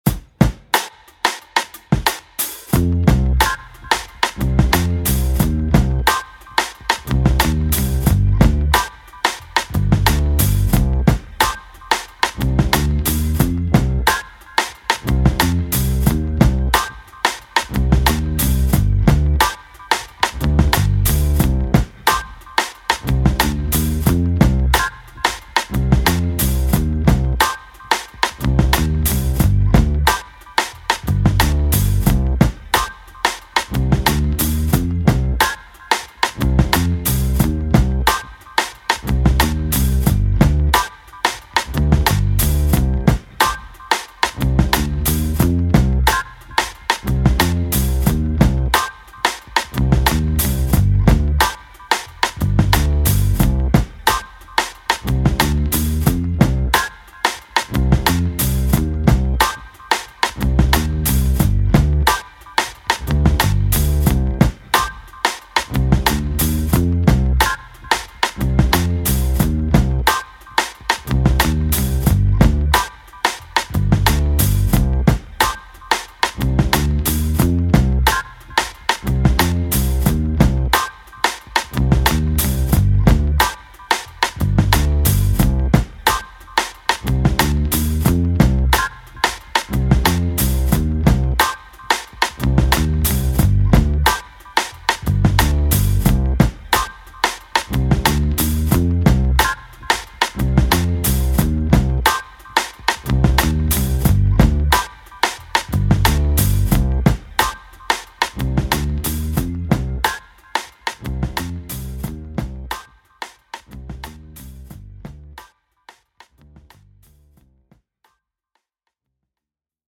Charming tip-toeing hip hop beat with a touch of the 60s.